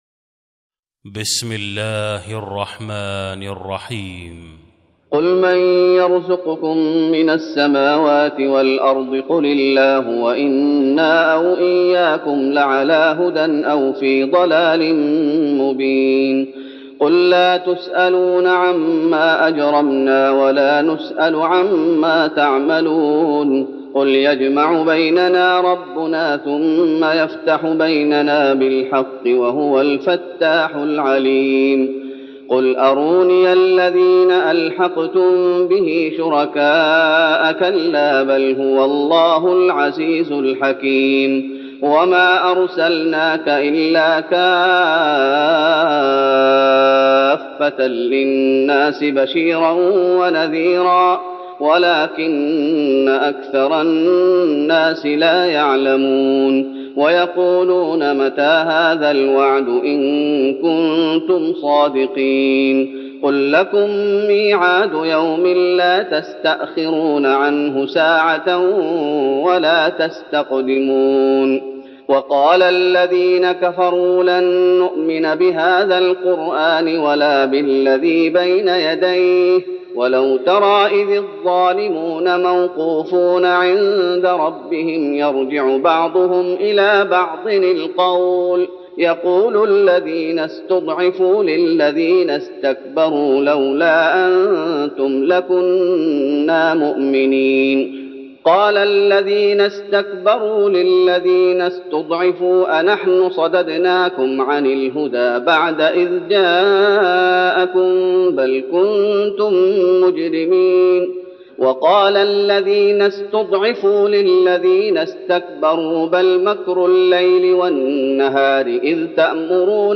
تراويح رمضان 1412هـ من سور سبأ (24-54) Taraweeh Ramadan 1412H from Surah Saba > تراويح الشيخ محمد أيوب بالنبوي 1412 🕌 > التراويح - تلاوات الحرمين